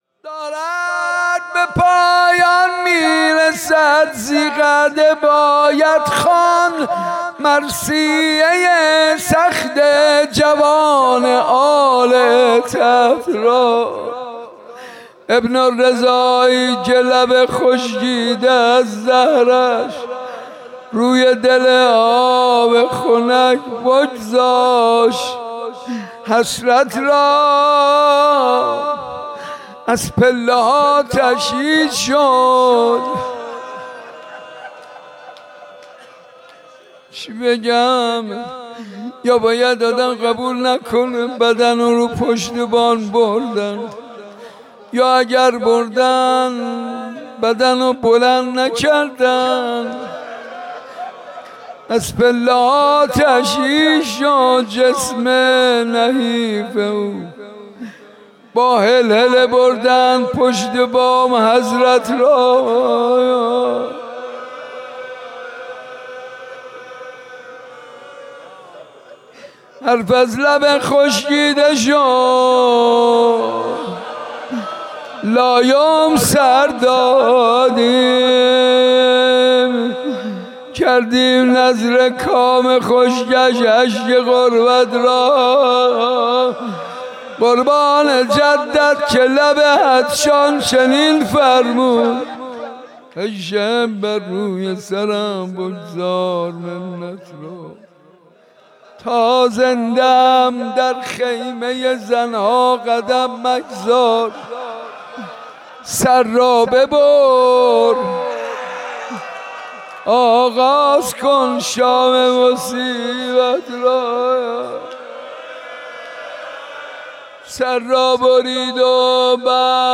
مداحی به سبک روضه اجرا شده است.